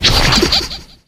brawl-stars-assets/50.219/sfx/squeak_ulti_01.ogg at e6c6782cce1518f8b7d4298dc430b6dfd8dbe688
squeak_ulti_01.ogg